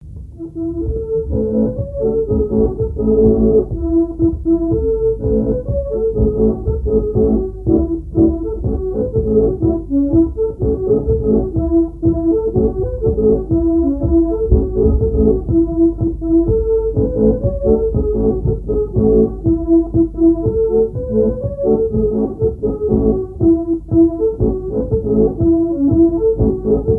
Bouin ( Plus d'informations sur Wikipedia ) Vendée
branle : courante, maraîchine
Répertoire à l'accordéon diatonique
Pièce musicale inédite